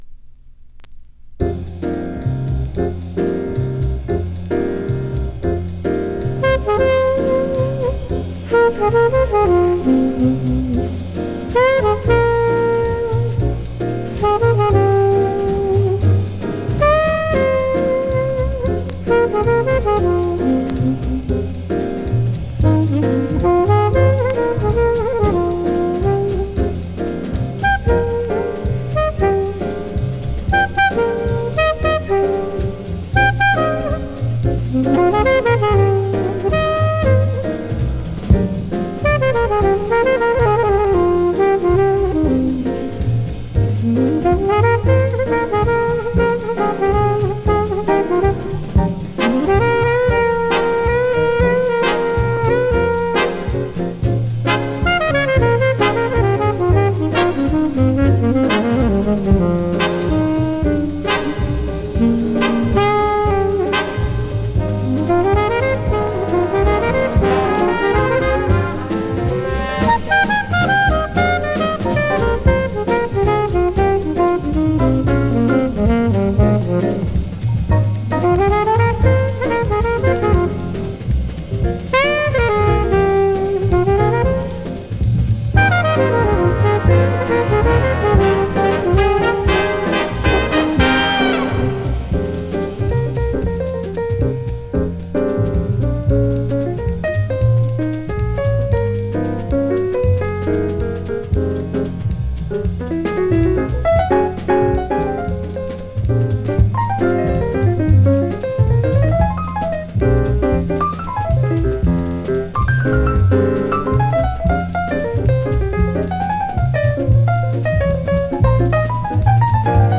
Trombone
Trumpet and Fluglehorn
French Horn
Drums
Done in a fleet 5/4 time signature